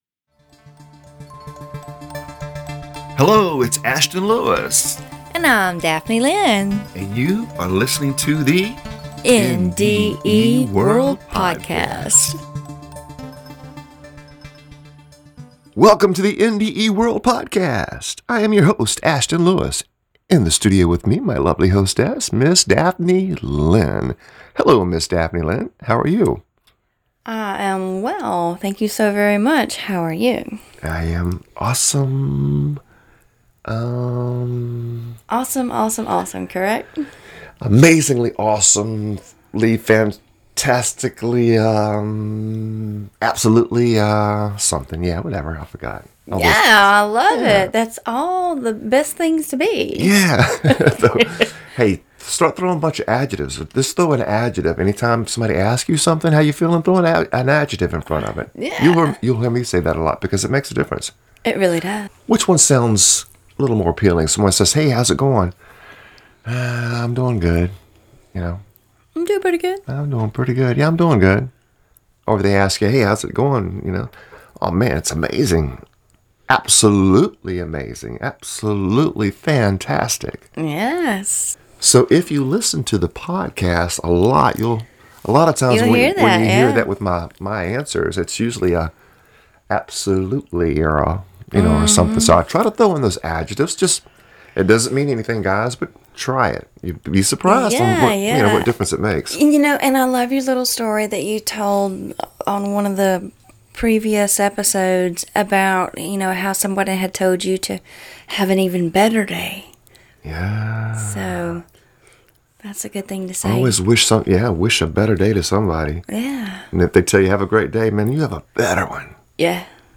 In this series, we will share the world of UDO pronounced (yoo' dō), with our listeners. UDO is the acronym for Universal Divine Oneness, and we will help you to reach that state of UDO.